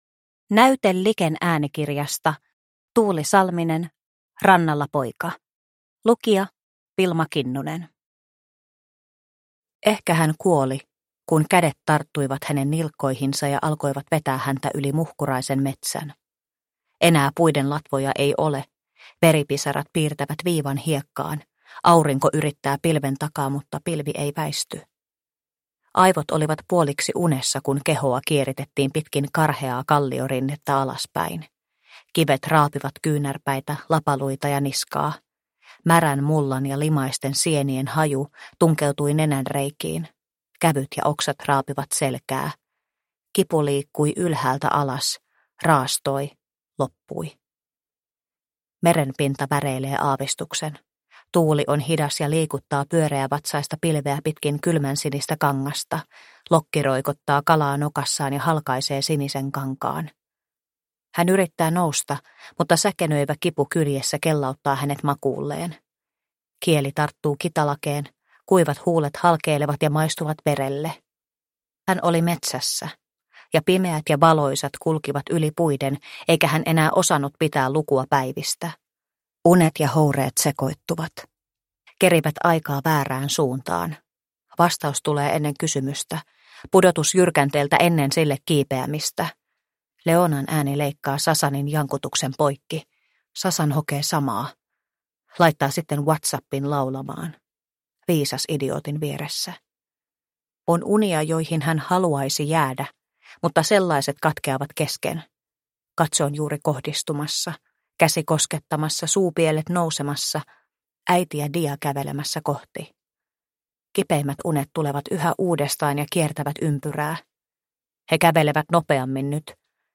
Rannalla poika – Ljudbok – Laddas ner